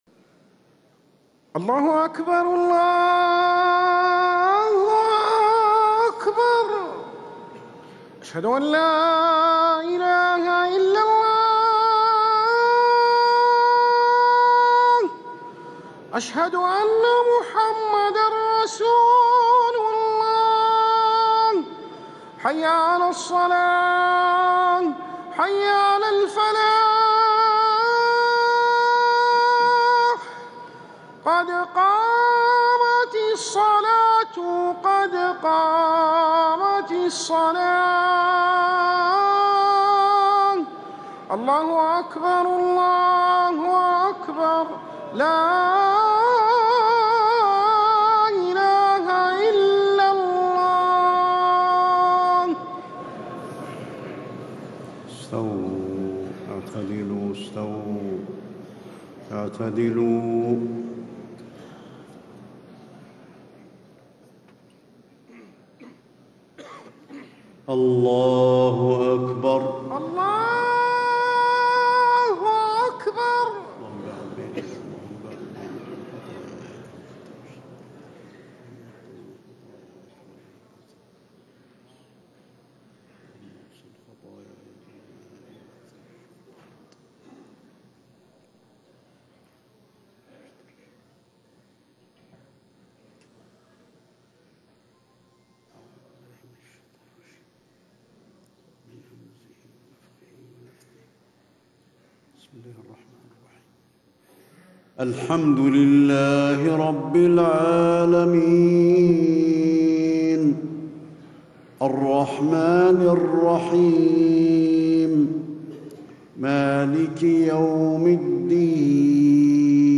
صلاة المغرب 1 ربيع الاخر 1437هـ سورتي الطارق و التين > 1437 🕌 > الفروض - تلاوات الحرمين